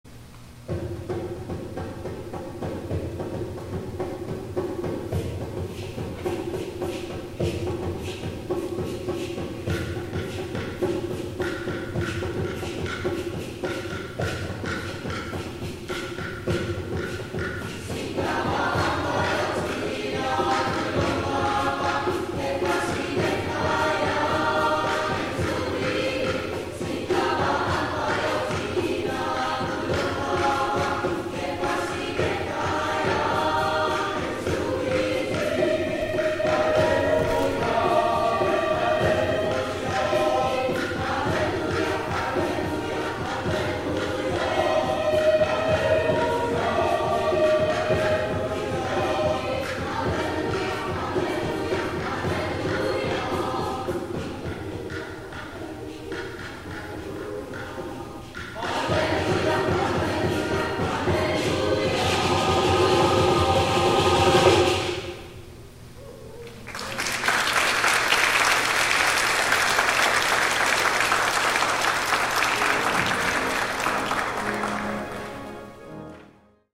*THE CHORAL RESPONSE
Singabahambayo  Traditional South African